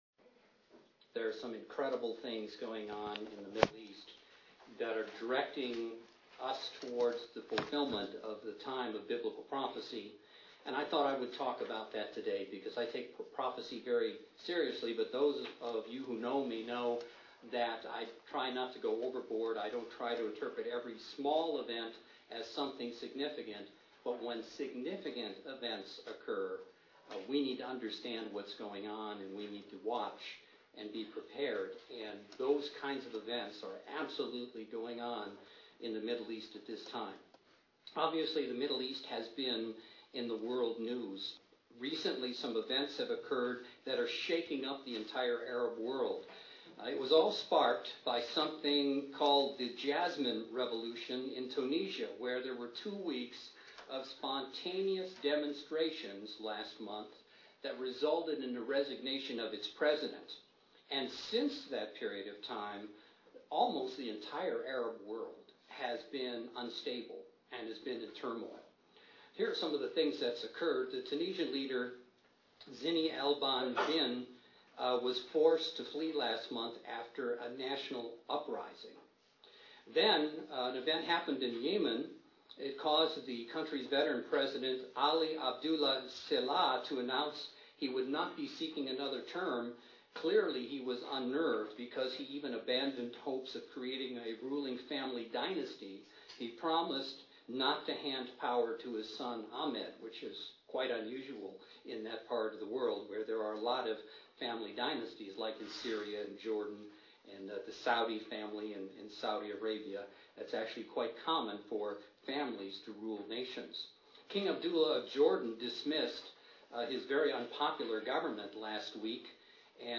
Recent event in Egypt and other Middle Eastern nations are a reminder that biblical prophecy is being fulfilled. This sermon looks at the book of Daniel, along with current news events, and discusses end time prophecy.